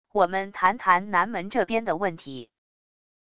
我们谈谈南门这边的问题wav音频